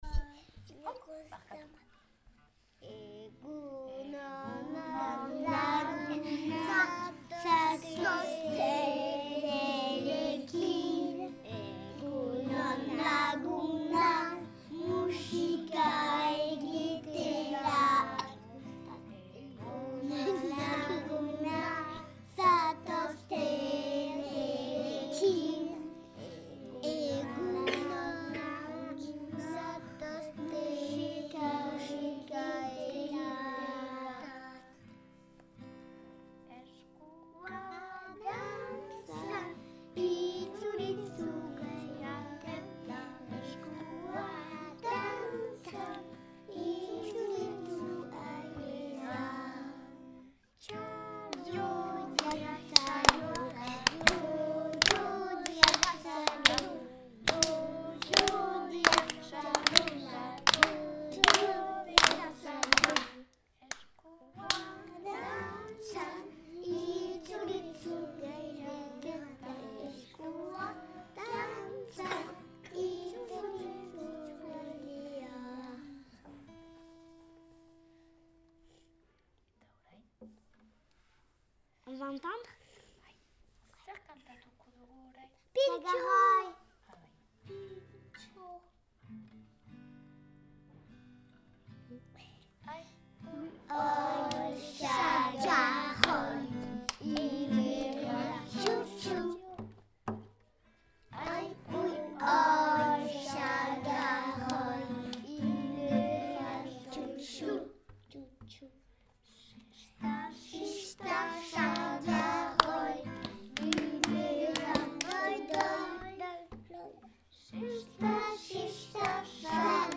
Lehen kantuak – Premières comptines